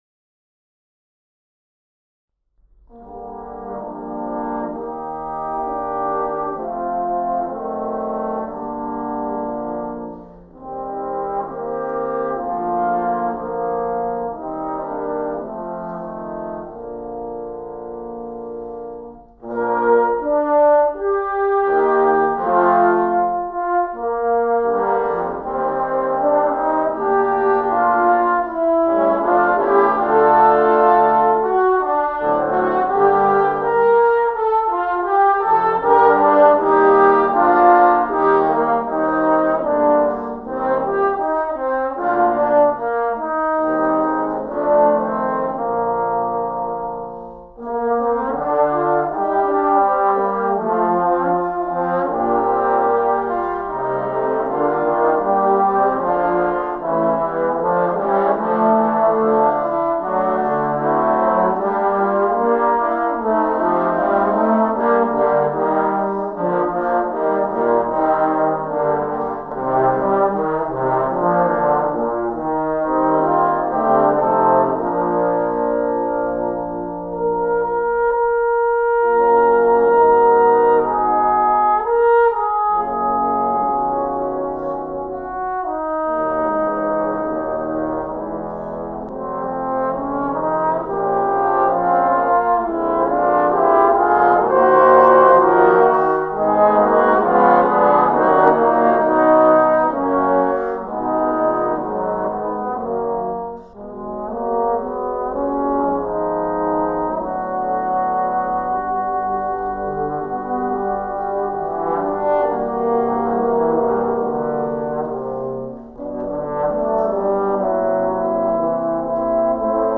10 Trombones (Dectets) Item